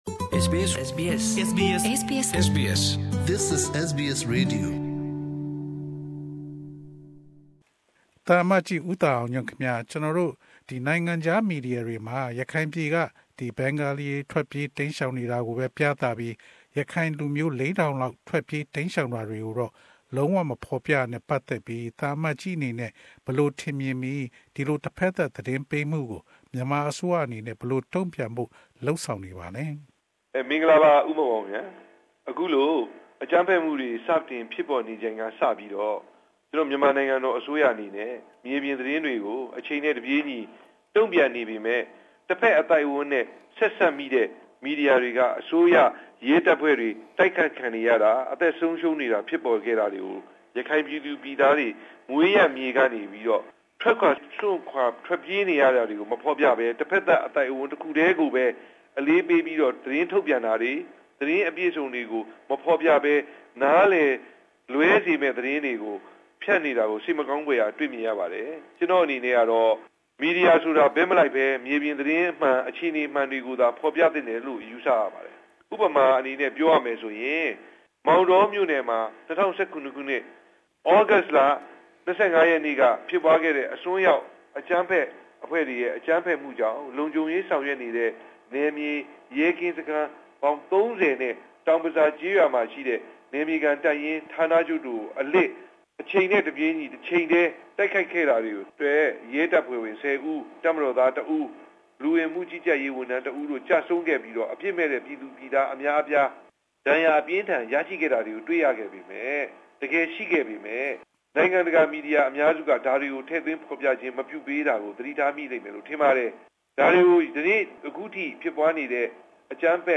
Myanmar Ambassador to Australia interview Part 1